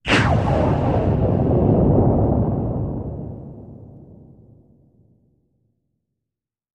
Water; Underwater Gunshot Explosion, With Reverb.